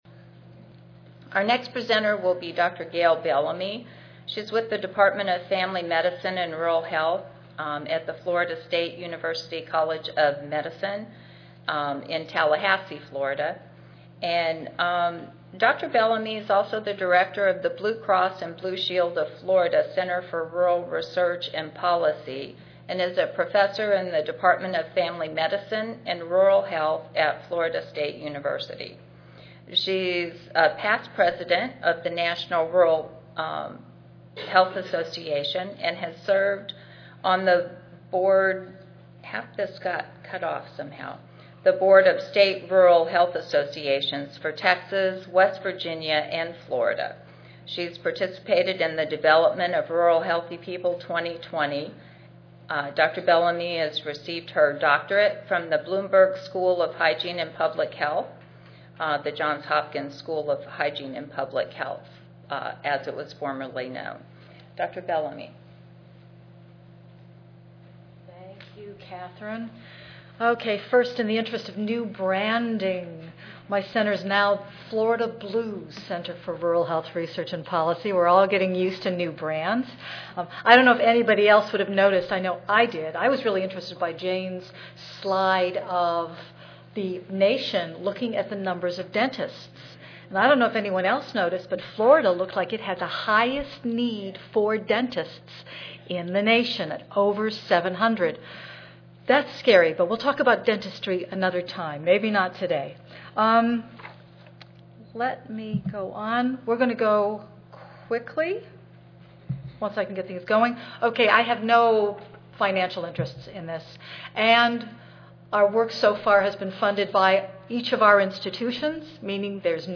3216.0 Healthy “Rural” People: How APHA’s State Affiliates Can Get Involved Monday, November 4, 2013: 12:30 p.m. - 2:00 p.m. Oral This session will begin by sharing the results of the Rural Healthy People 2020 survey, the top 10 health focus areas for rural, and how those differed by census region. Additionally it will move into a discussion of how the project can be of use to state affiliates with regard to accreditation related rural public health workforce training.